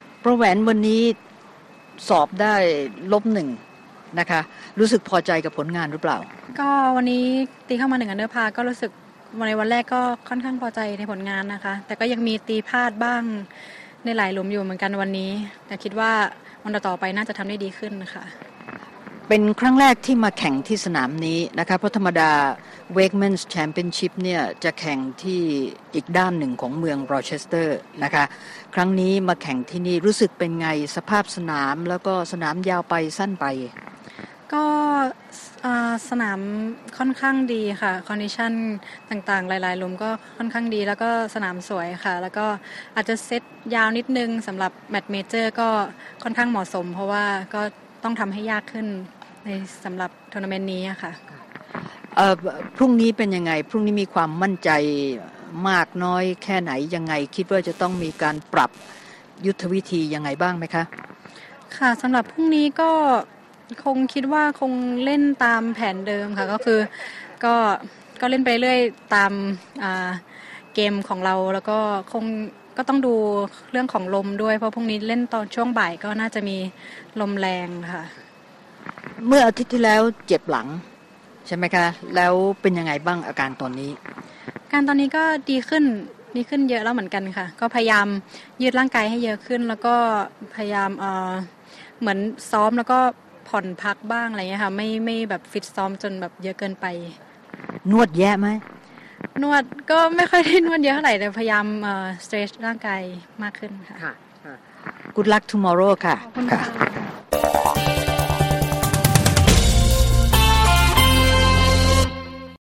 Interview LPGA